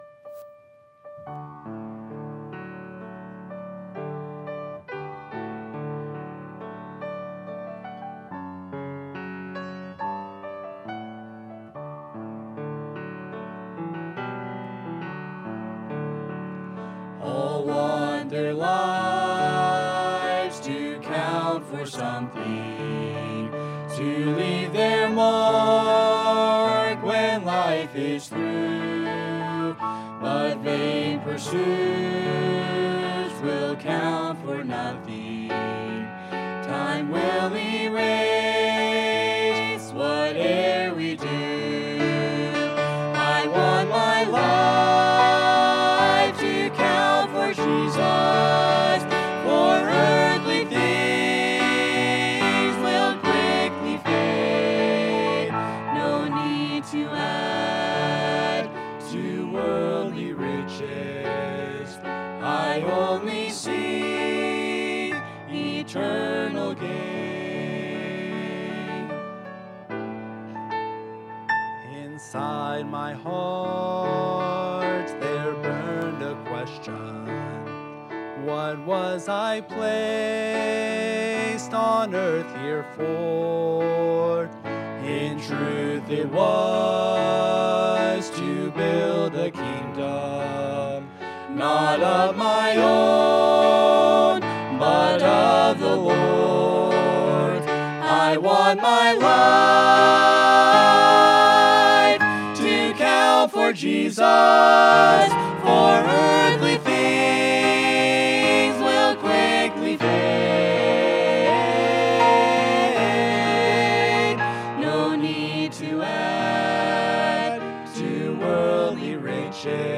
Ordination Service | Sunday PM